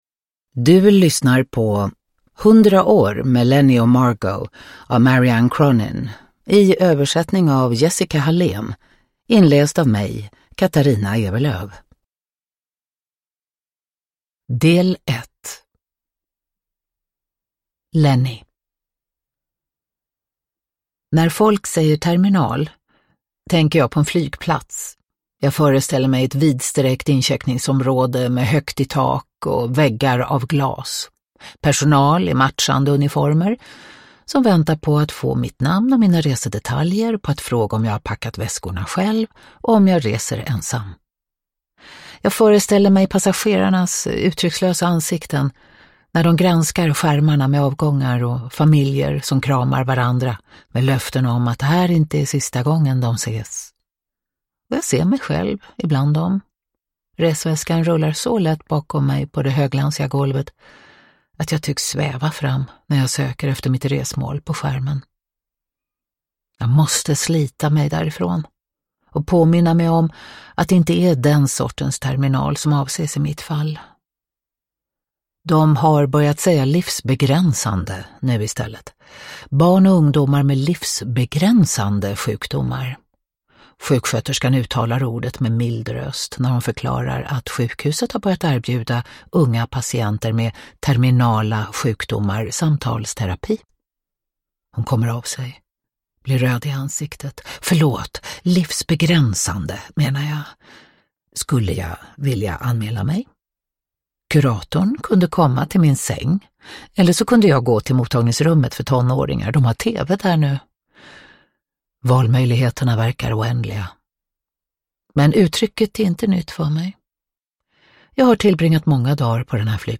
Uppläsare: Katarina Ewerlöf